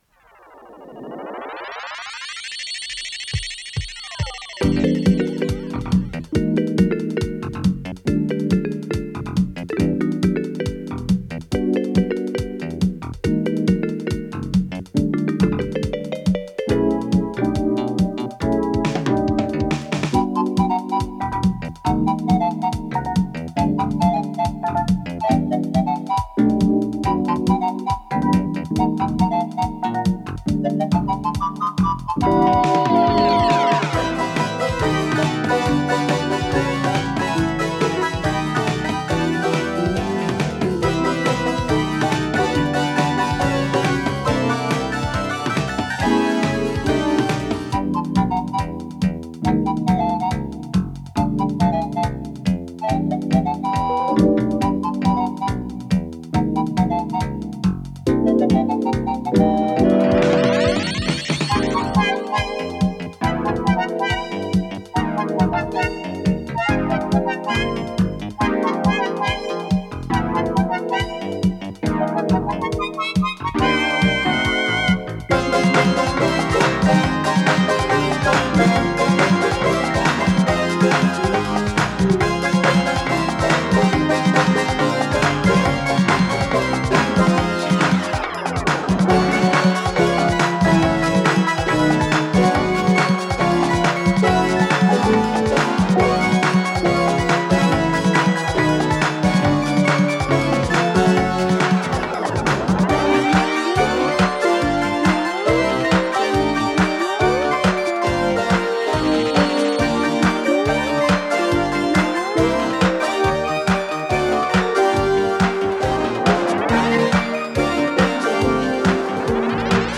с профессиональной магнитной ленты
ПодзаголовокПьеса
ВариантДубль моно